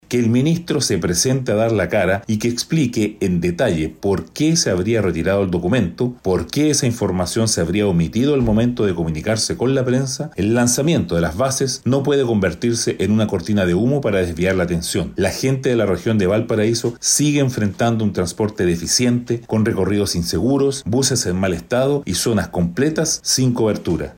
Por otra parte, el diputado de Evópoli, Hotuiti Teao, emplazó directamente al ministro Muñoz para aclarar la omisión de información respecto al proceso, haciendo un llamado a no tapar la realidad con el nuevo anuncio.